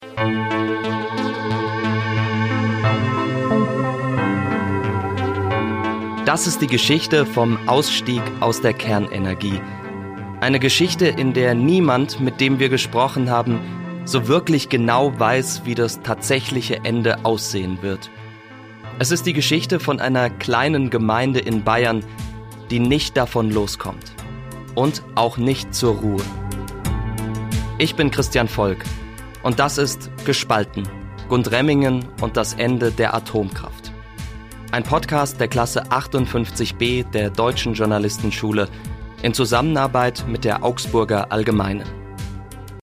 Genres: Documentary, News, Society & Culture
Trailer: